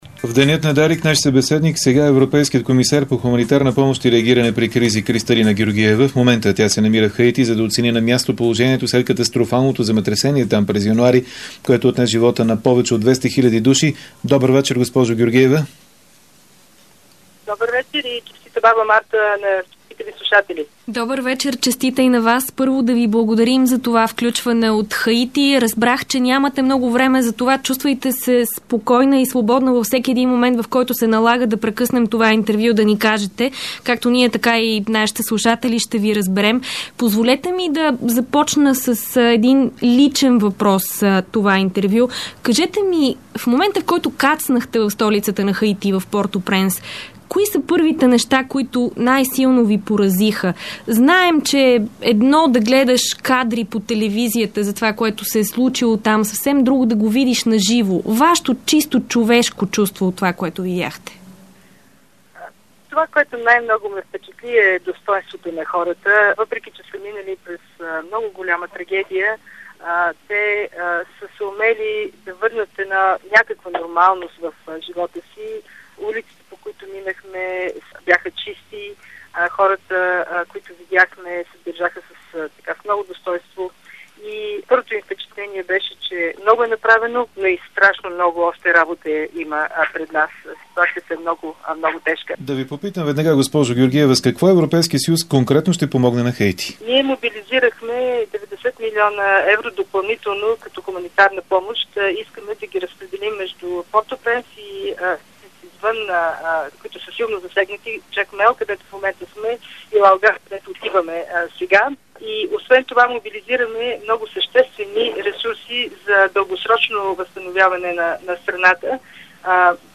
Интервю с Кристалина Георгиева